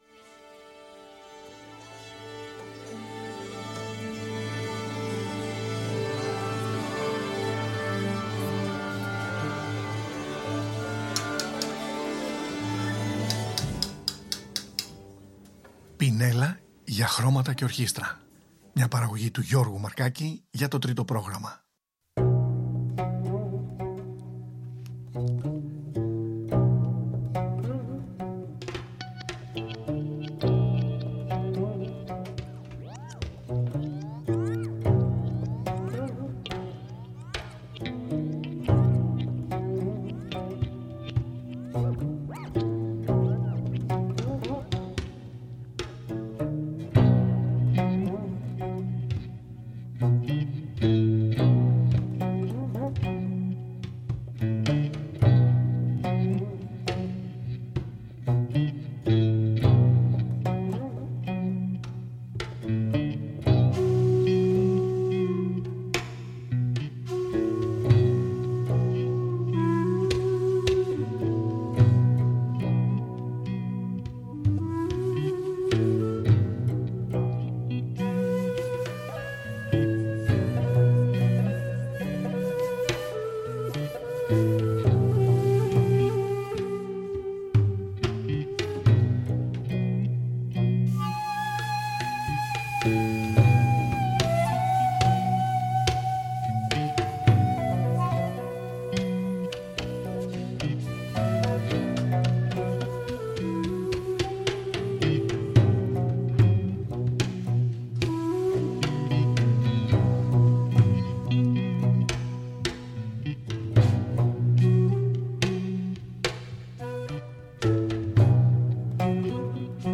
σολίστα στο Geomungo (αρχαίο σαντούρι της Άπω Ανατολής)
κιθάρα, beat mixing
φωνητικά, janggu, percussion & taepyeongso